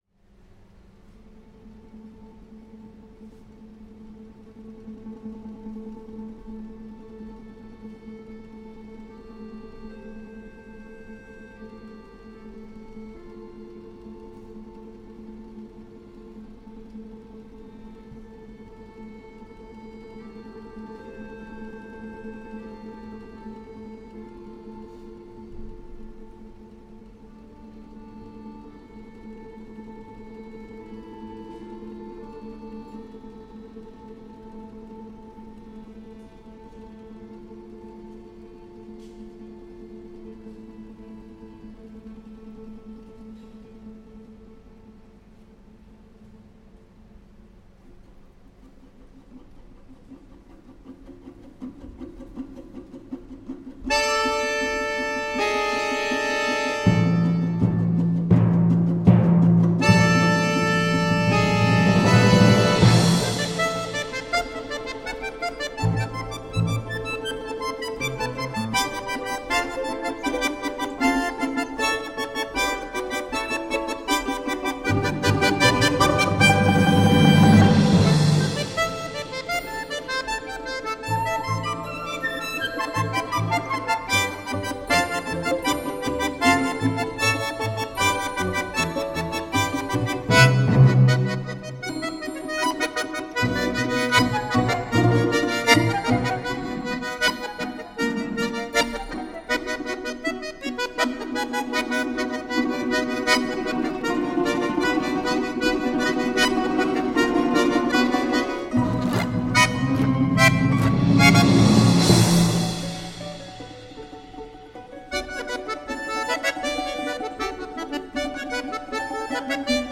«Тульская кадриль» — это произведение для оркестра русских народных инструментов и баяна соло, в которой показан пляс, но не туляков.
В музыке повествуется о татарском полоне, как «злы татарове дуван дуванили». Изображается пляска татарских захватчиков.